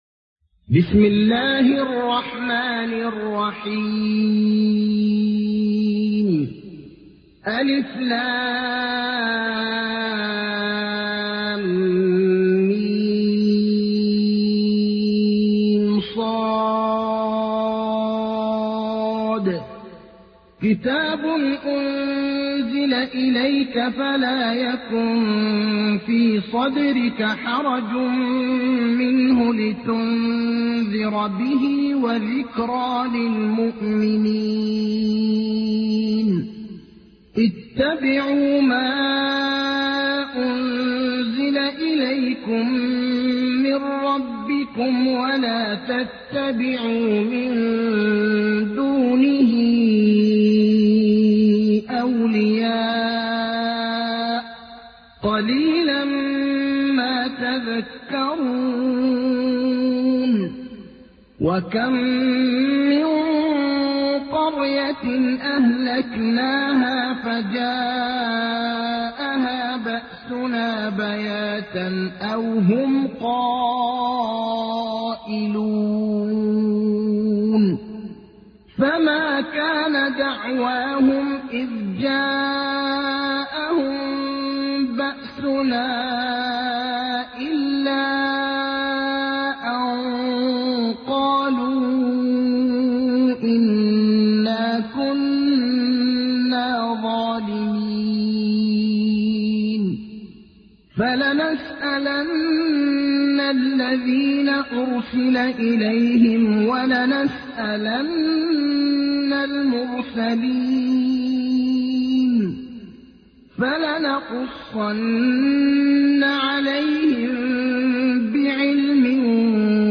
تحميل : 7. سورة الأعراف / القارئ ابراهيم الأخضر / القرآن الكريم / موقع يا حسين